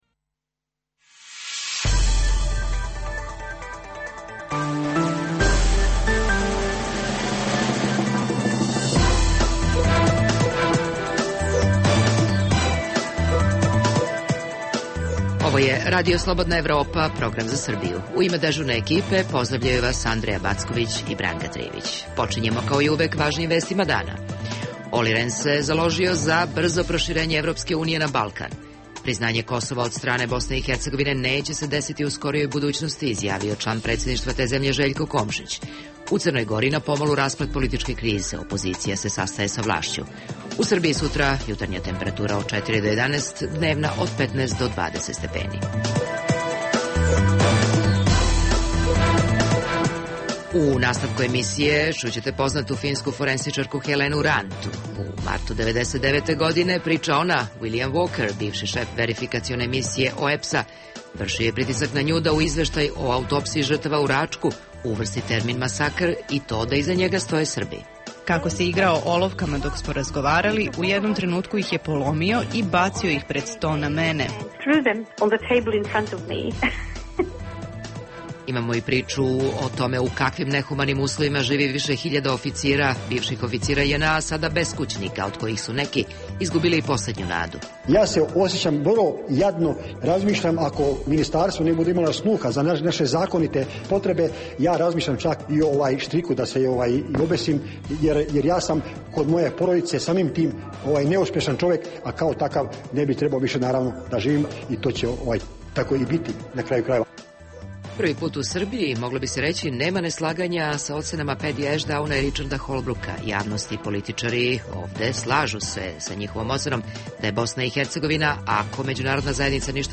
U tematskom delu emisije čućete intervju sa poznatom finskom forenzičarkom Helenom Rantom; odgovor na pitanje zašto zapadni predstavnici vide BiH kao najveće bure baruta na Balkanu; u kakvim nehumanim uslovima žive bivši oficiri JNA, sada beskućnici u Srbiji.